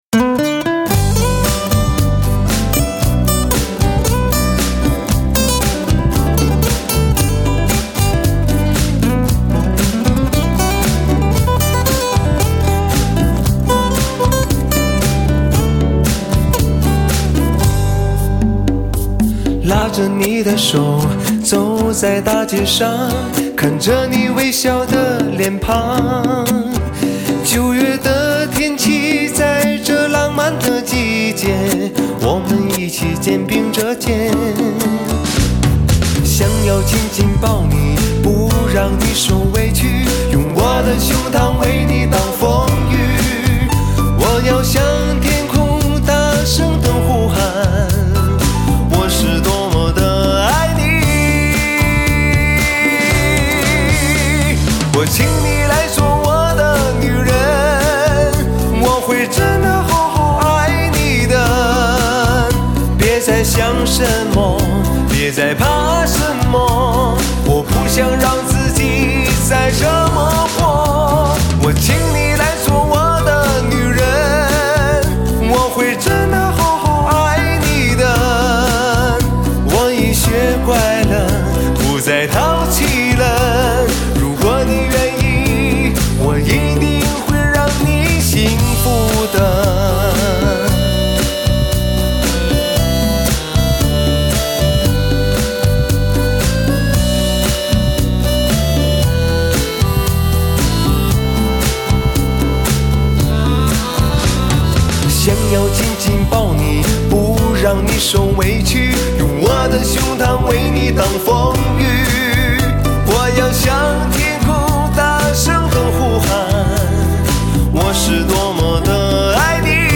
一个用心灵去歌唱的感性歌手，